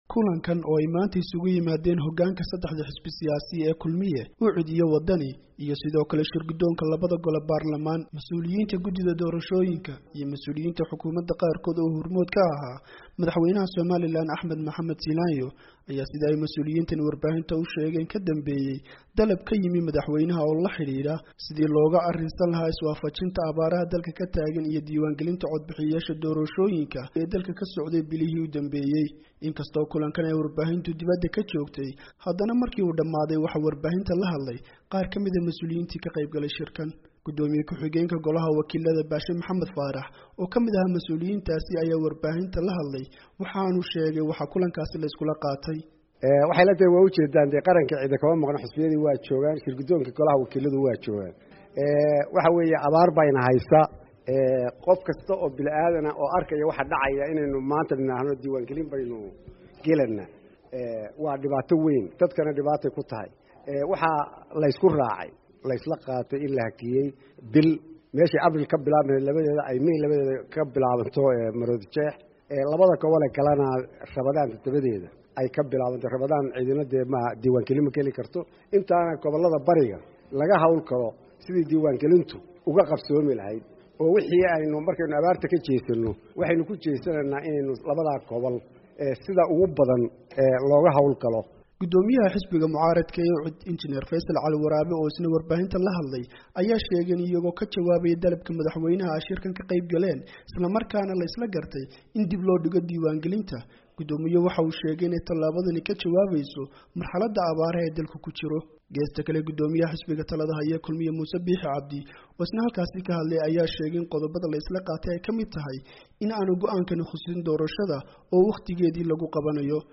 Warbixin: Doorashada Somaliland